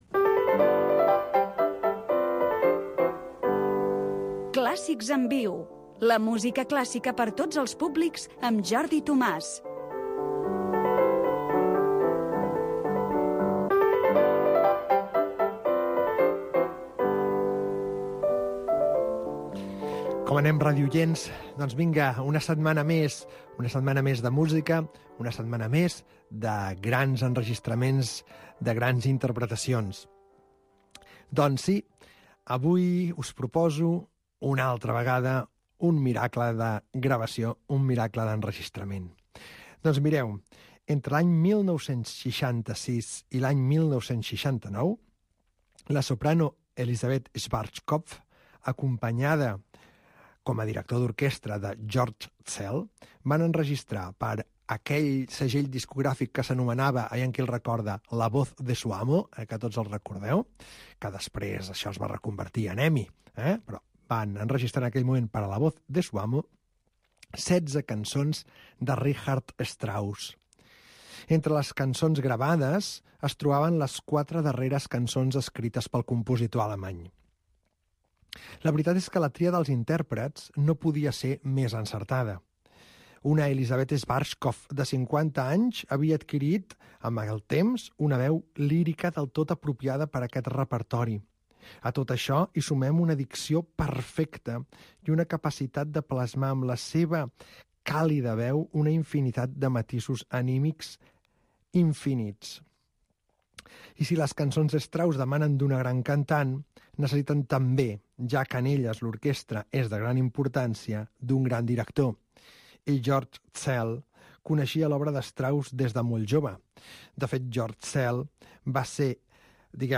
Clàssics en viu, programa de música clàssica per tots els públics.